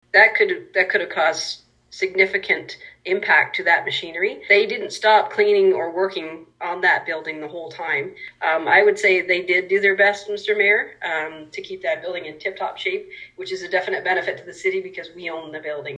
Last night Quinte West’s Corporate Finance Committee was asked for financial support to pay some utility costs for the ‘Y’ in Trenton.
Councillor Lesley Roseblade said the ‘Y’ couldn’t just walk away from the building and should be helped financially.